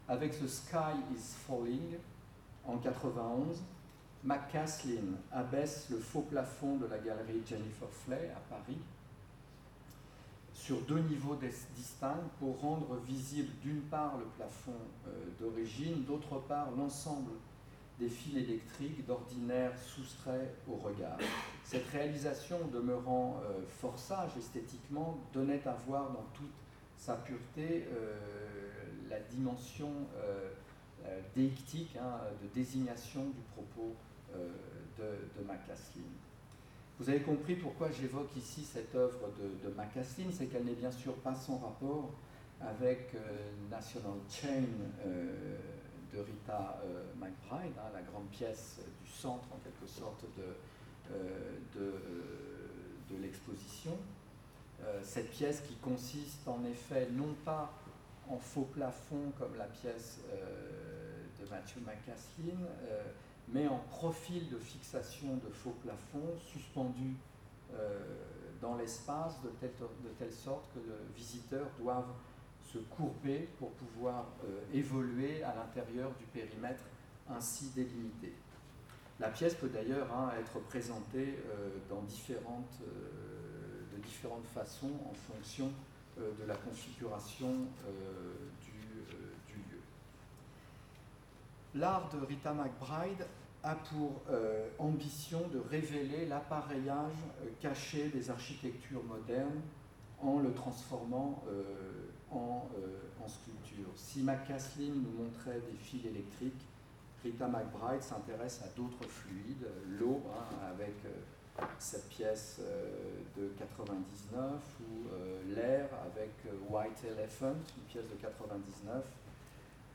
L’Institut d’art contemporain poursuit son cycle de conférences critiques et théoriques en lui donnant la forme d’un séminaire, et en l’orientant sur des questions d’ordre sculptural, à travers les enjeux de l’exposition Fabricateurs d’espaces, dans laquelle l’espace est considéré comme matière même de la sculpture.